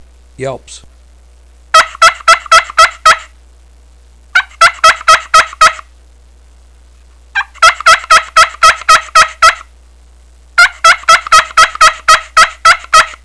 ccpushpinyelps13.wav